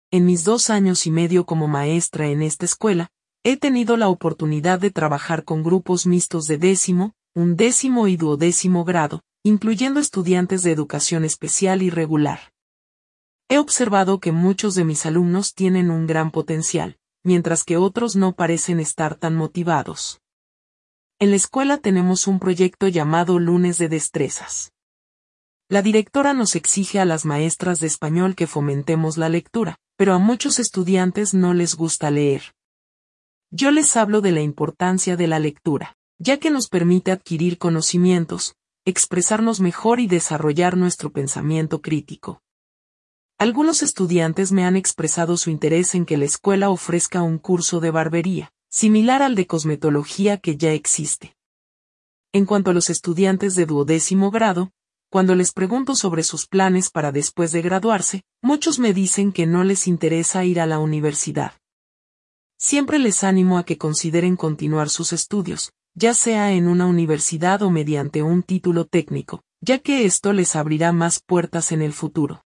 El podcast es el testimonio de una maestra de la escuela publica de Puerto Rico.